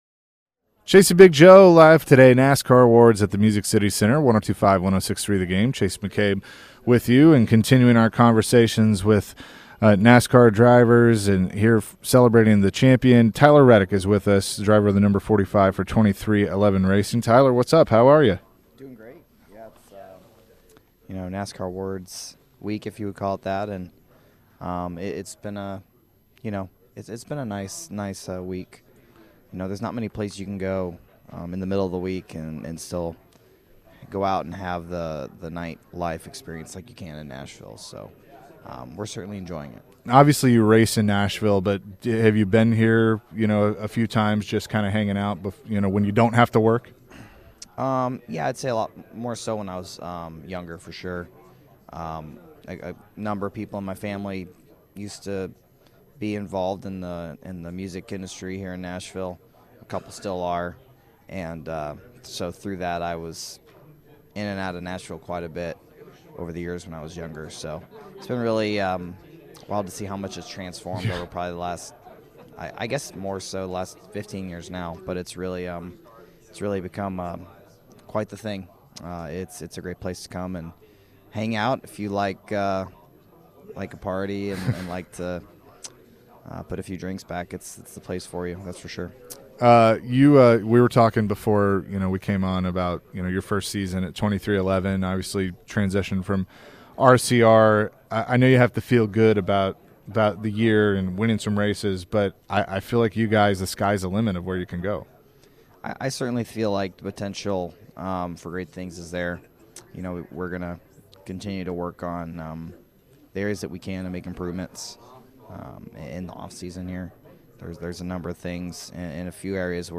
at today's NASCAR Awards celebration